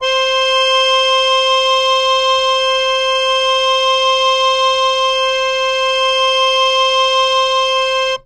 interactive-fretboard / samples / harmonium / C5.wav
C5.wav